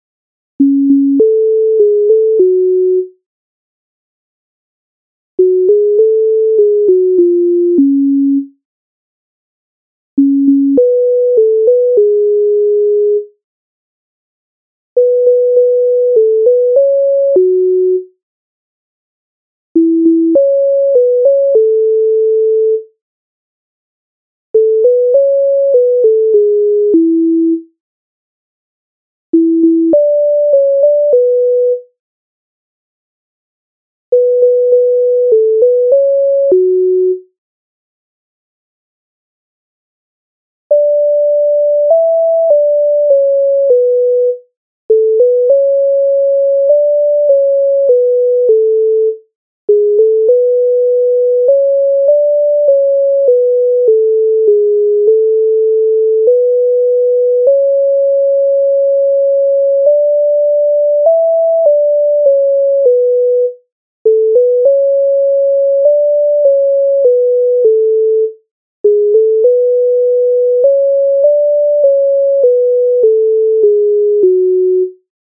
MIDI файл завантажено в тональності fis-moll